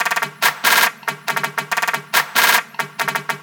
DDWV CLAP LOOP 3.wav